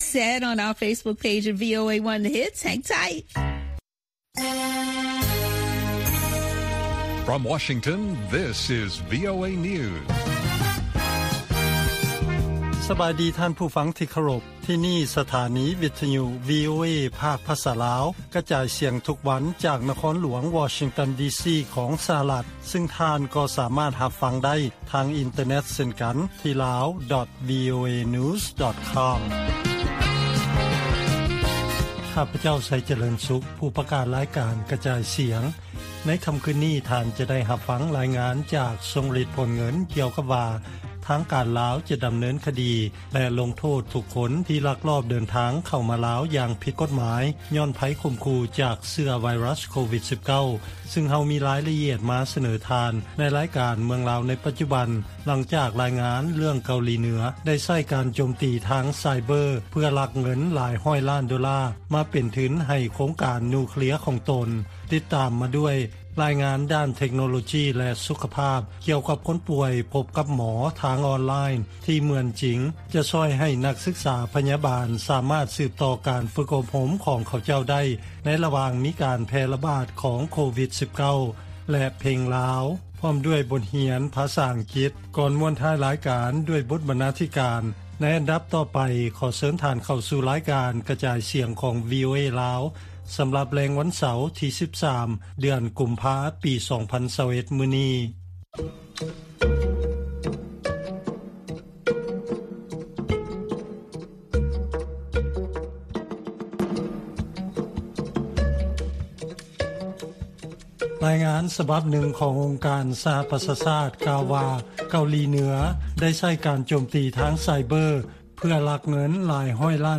ລາຍການກະຈາຍສຽງຂອງວີໂອເອ ລາວ
ວີໂອເອພາກພາສາລາວ ກະຈາຍສຽງທຸກໆວັນ.